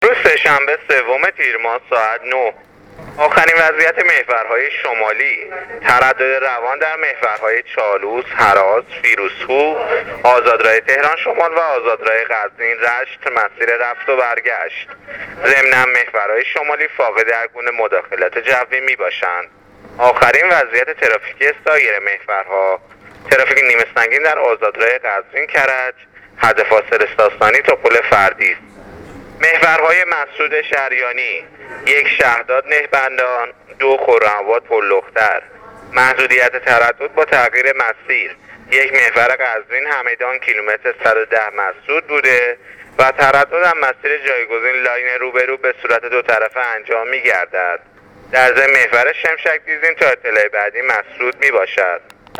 گزارش رادیو اینترنتی از وضعیت ترافیکی جاده‌ها تا ساعت ۹ سه‌شنبه سوم تیرماه